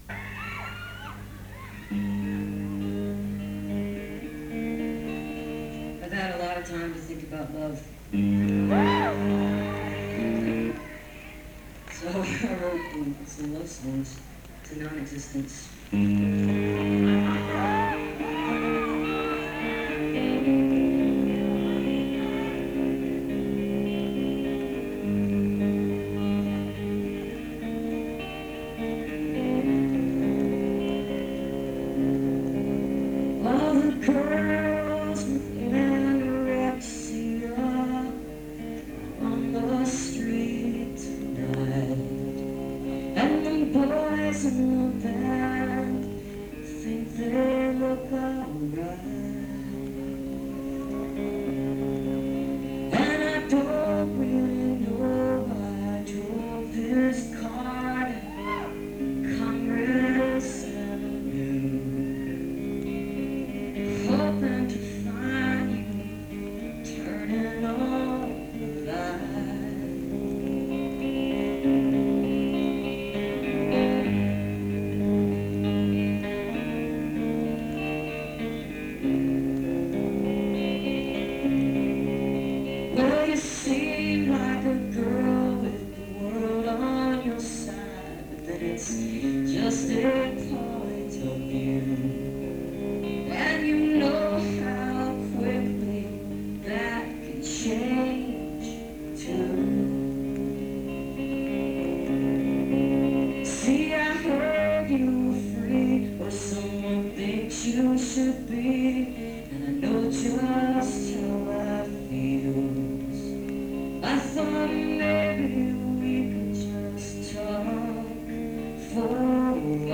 paolo solieri - santa fe, new mexico